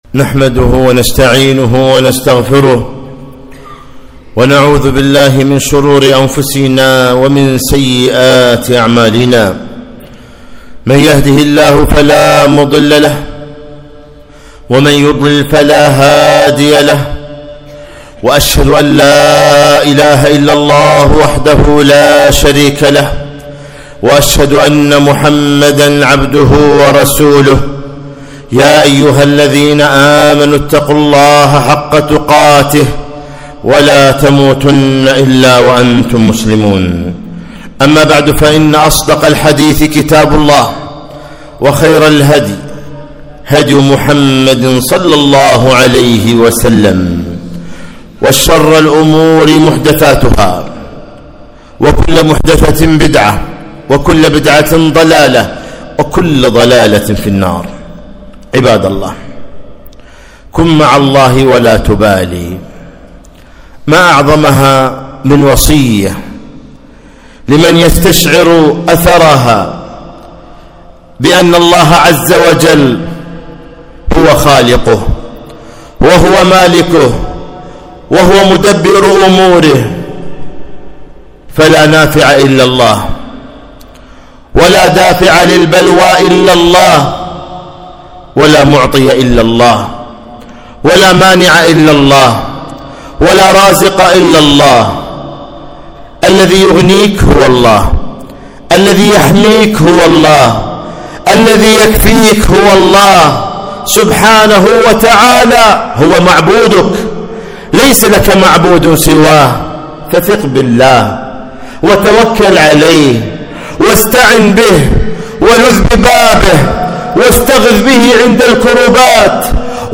خطبة - كن مع الله ولا تبالِ